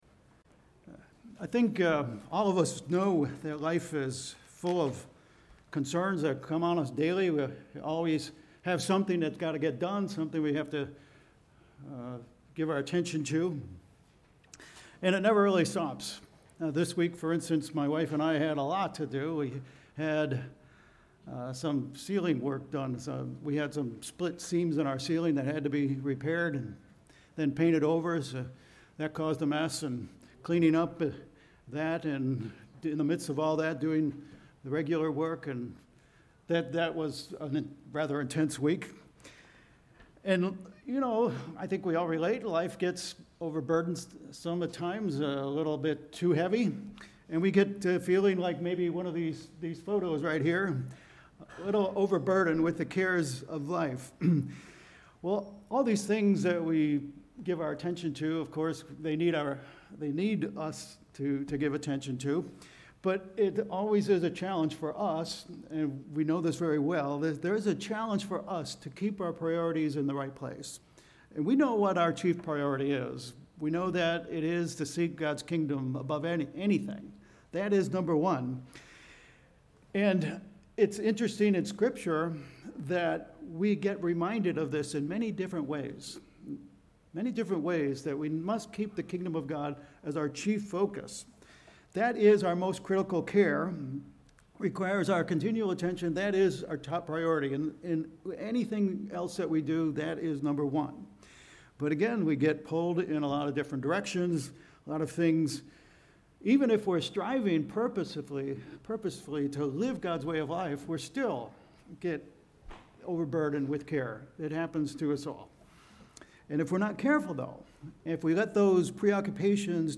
Sermons
Given in Vero Beach, FL